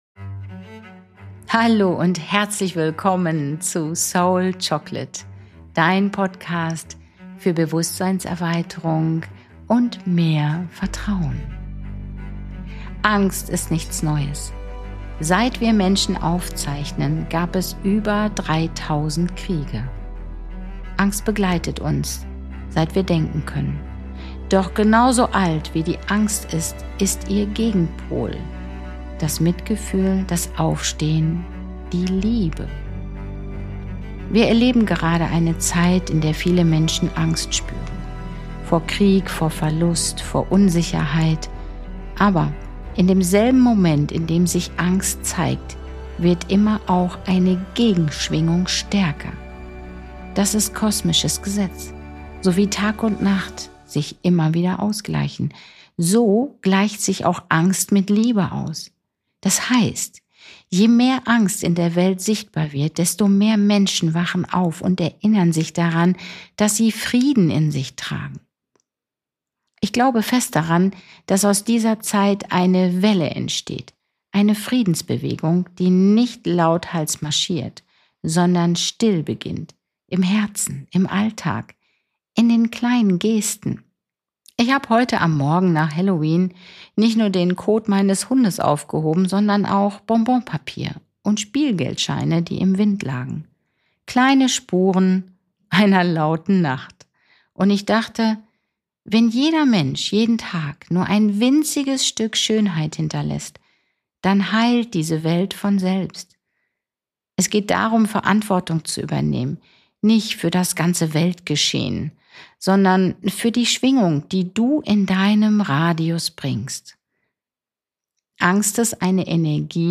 Eine ruhige, tief berührende Folge über Bewusstsein,